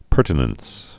(pûrtn-əns)